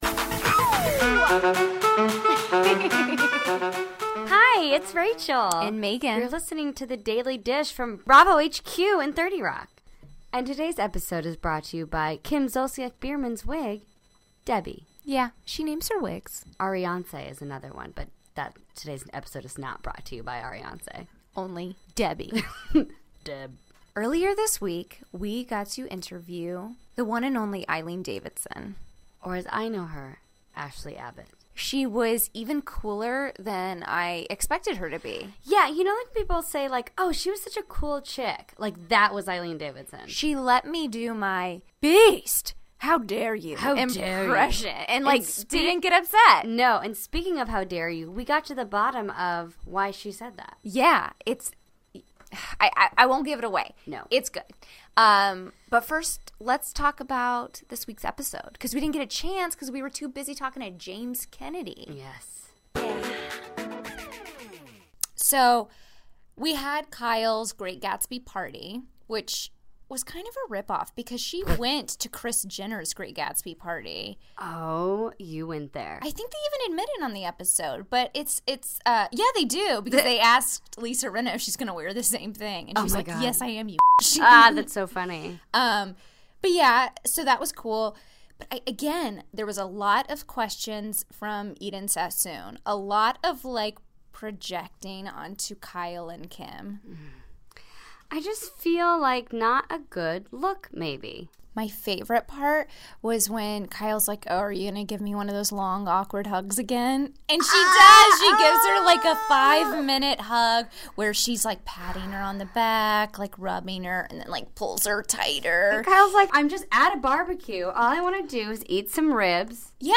Friday, February 3, 2017 - From Bravo HQ in New York City, Eileen Davidson is in the studio dishing all the dirt on this season of RHOBH. She lets us know where she is with new ‘Wife Dorit Kemsley, reveals the most terrifying part of filming, and teases that panty-gate is far from over.